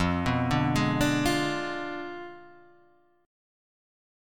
F Diminished 7th